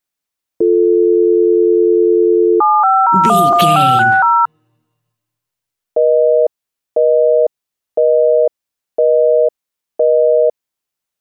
Telephone tone redial 8 numbers busy
Sound Effects
phone